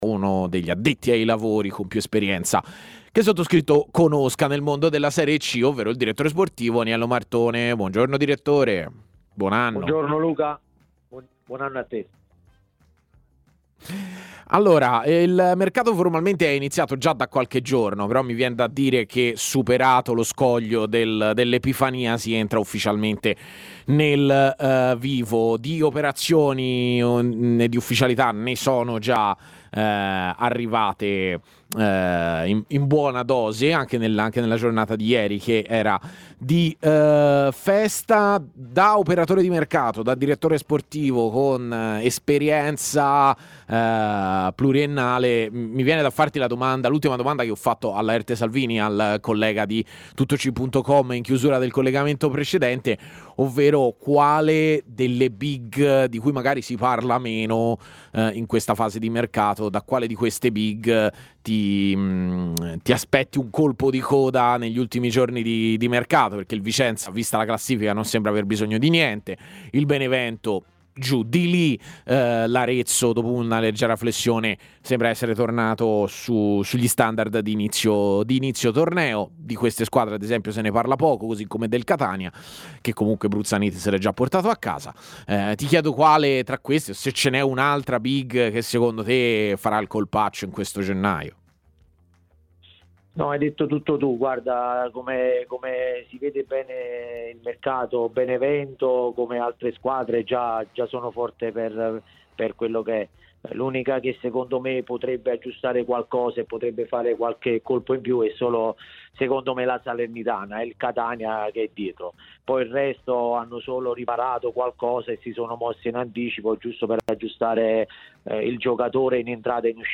Ospite della trasmissione A Tutta C, su TMW Radio